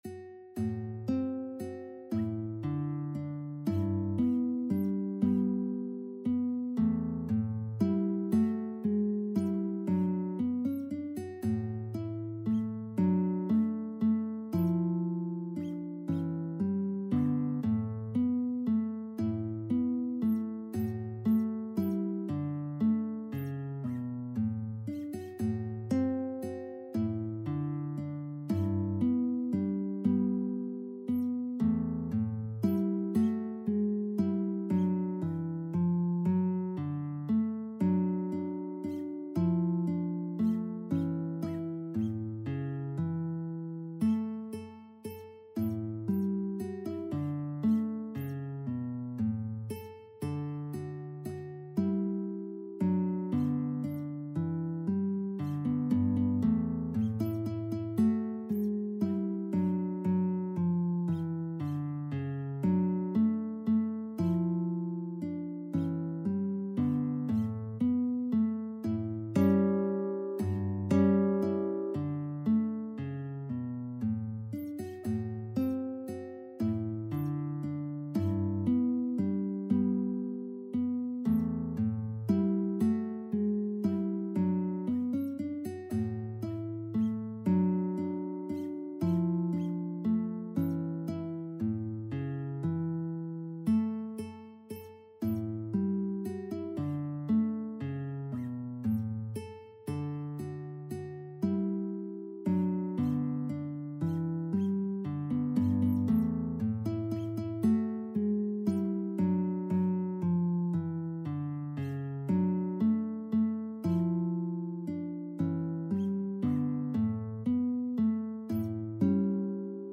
Guitar version
3/4 (View more 3/4 Music)
Moderate Waltz Tempo = c. 116
Guitar  (View more Intermediate Guitar Music)
Traditional (View more Traditional Guitar Music)
Rock and pop (View more Rock and pop Guitar Music)